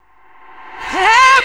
VOXREVERS2-L.wav